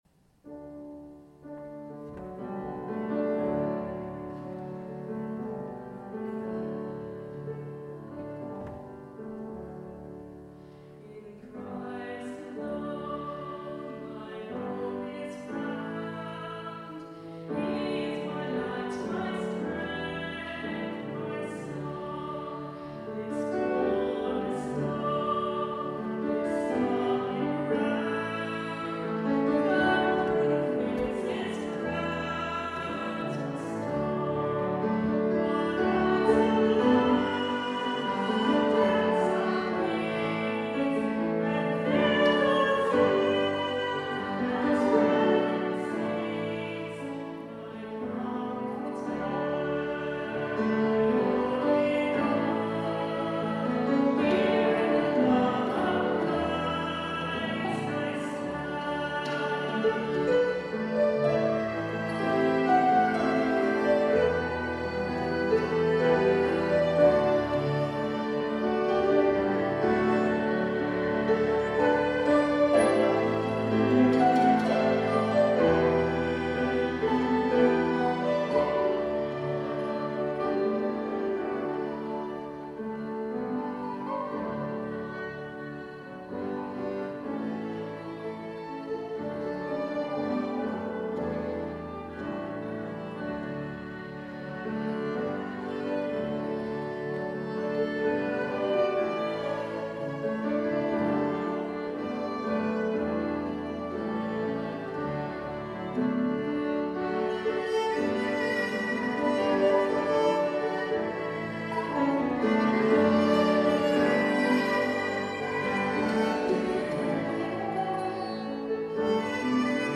This was part of a concert by the Inner Vision Orchestra at Keele University on Wednesday 16th March 2022. I sang and played accordion.